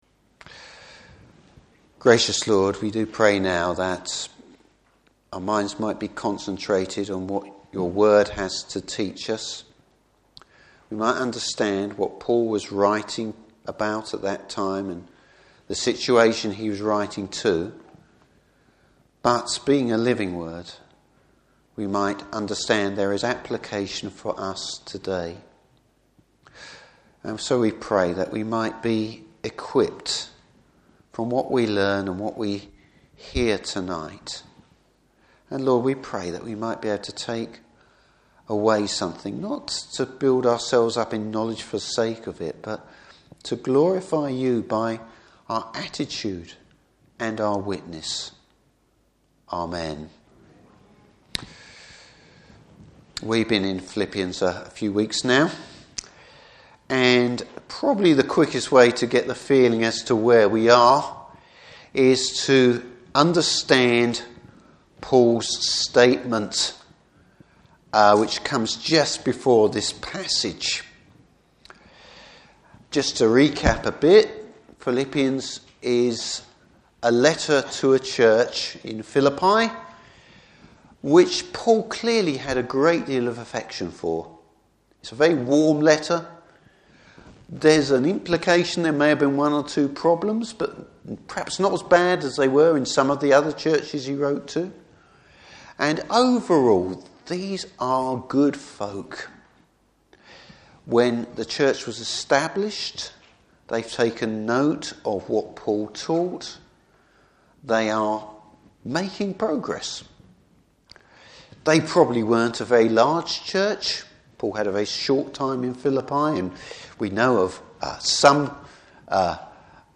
Service Type: Evening Service Being realistic and keeping one’s focus on God.